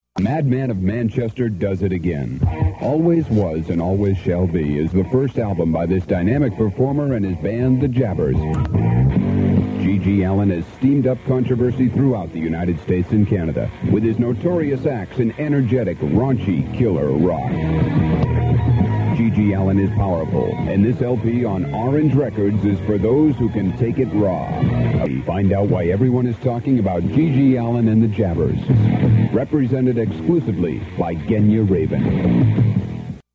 BL_GG_Allin_-_Radio_Ad.mp3